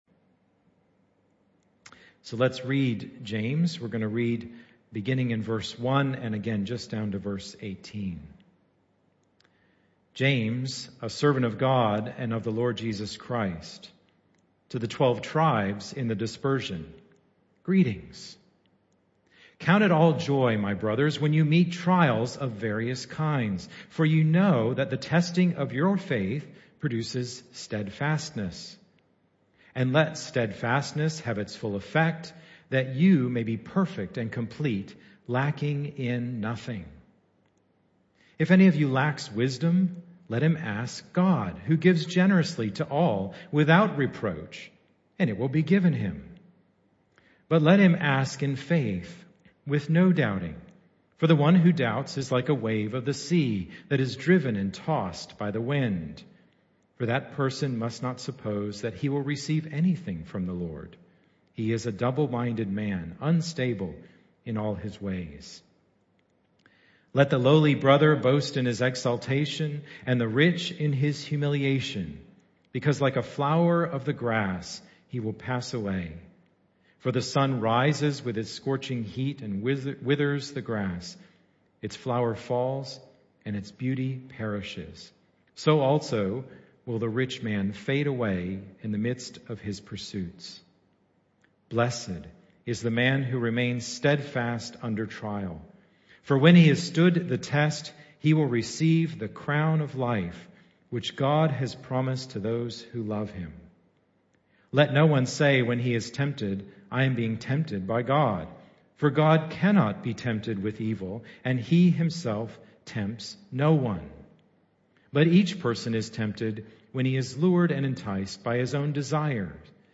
James 1:1-18 Service Type: Morning Service Bible Text